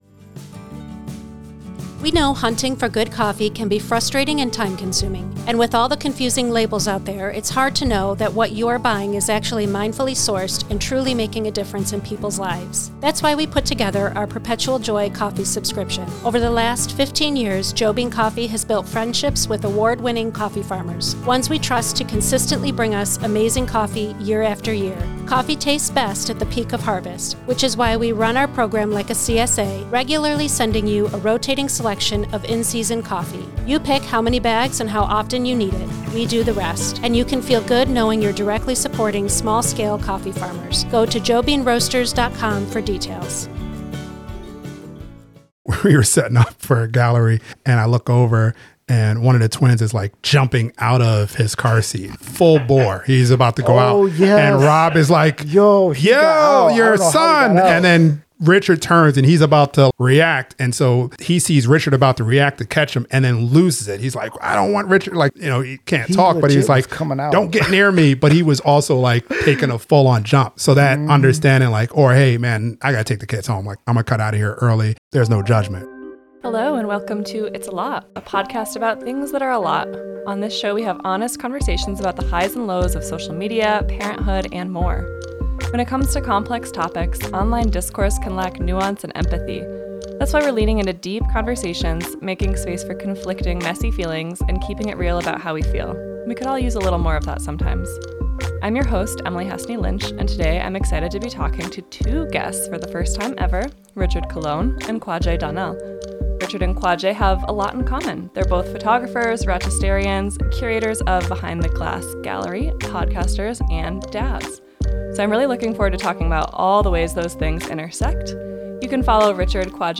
Behind the Glass Gallery podcast is a monthly talk with the current month's BTG Roster. Artists are interviewed about their submissions and we dive deep into their process, inspiration and thought process centralized around their artwork in the Behind the Glass Gallery located in the heart of Downtown Rochester NY.